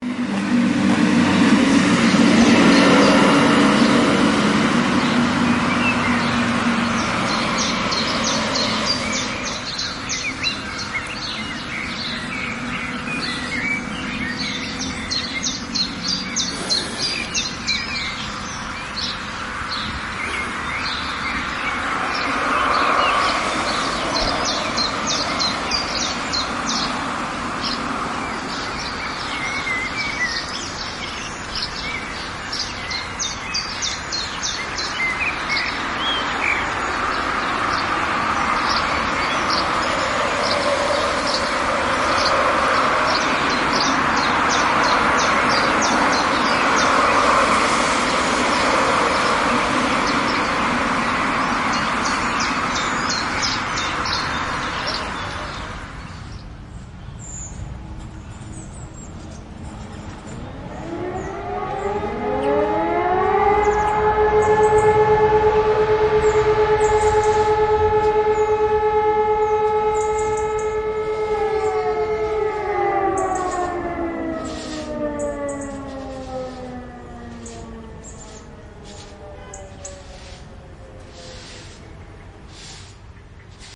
Urban Village Ambiance
Urban Village Ambiance is a free ambient sound effect available for download in MP3 format.
Urban Village Ambiance.mp3